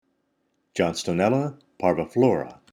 Pronunciation/Pronunciación:
John-sto-nél-la par-vi-flò-ra